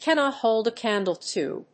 アクセントcannót hóld a cándle to…